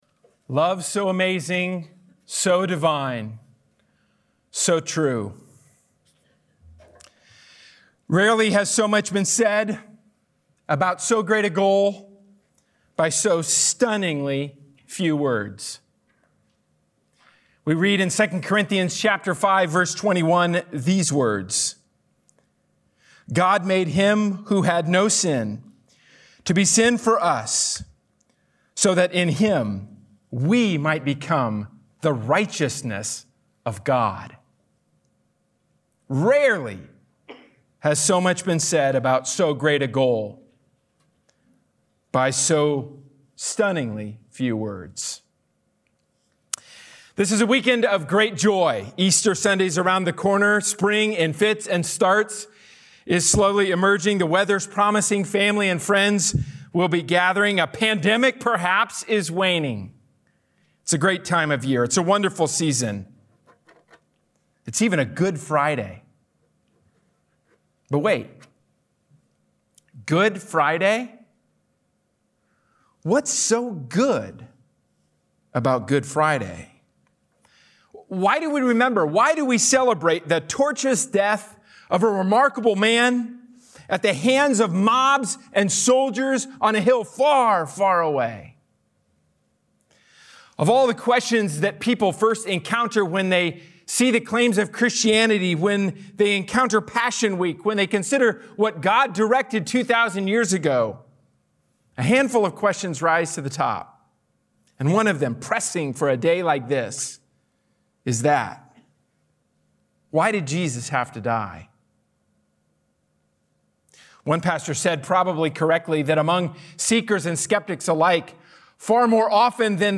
A sermon from the series "A New Start." God offers a brand new reality to those who are in Christ.